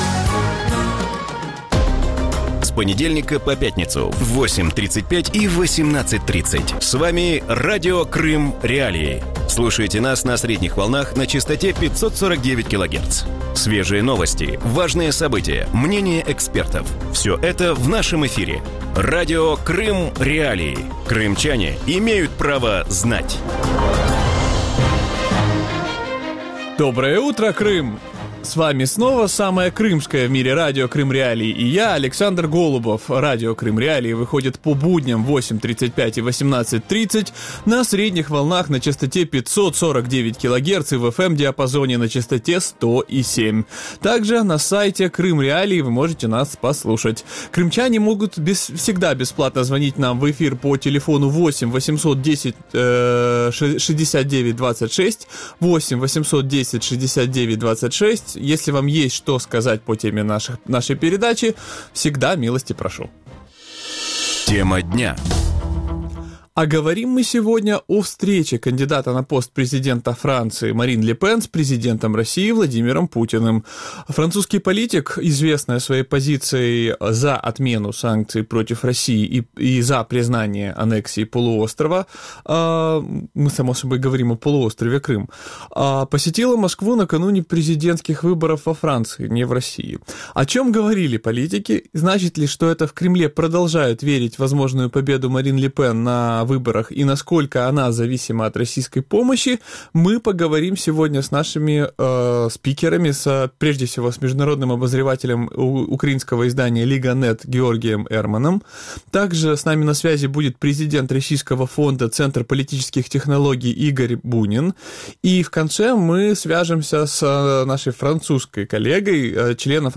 Утром в эфире Радио Крым.Реалии говорят о встрече кандидата на пост президента Франции Марин Ле Пен с президентом России Владимиром Путиным.